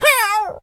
dog_hurt_whimper_howl_09.wav